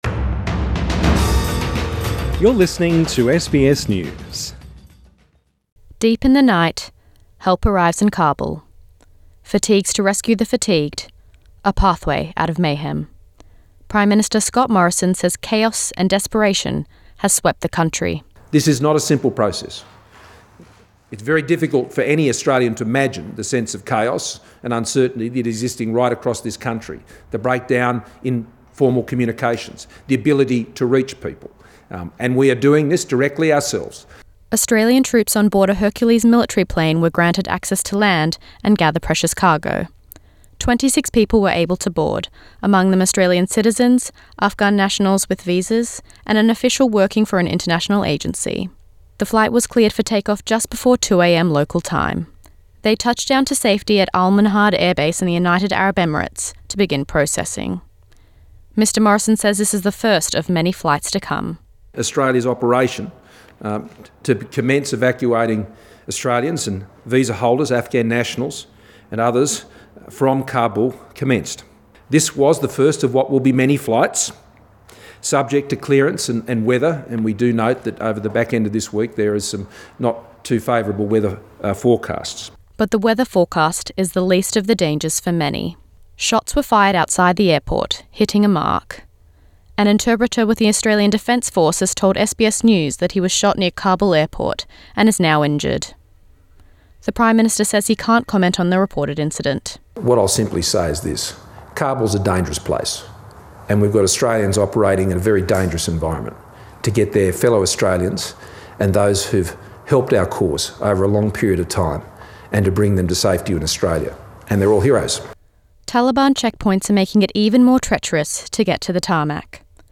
PM Scott Morrison and Foreign Affairs minister Marise Payne address the media ( Source: SBS